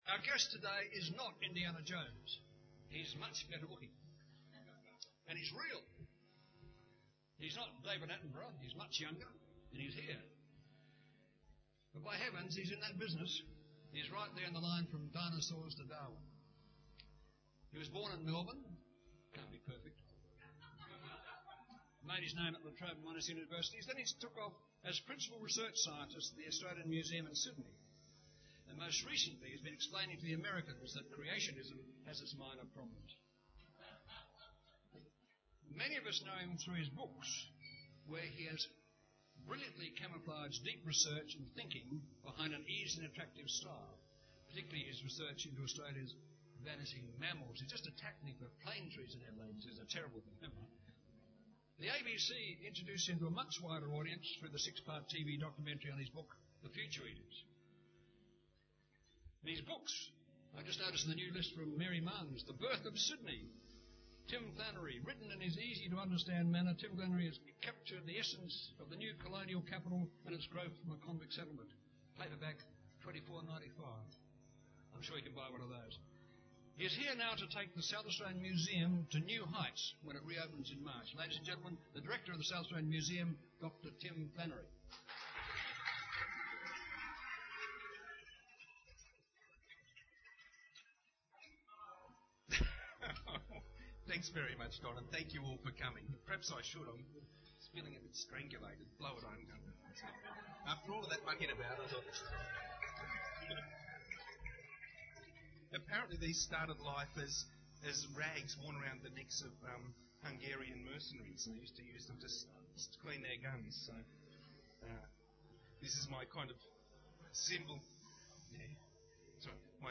Event Category: Luncheons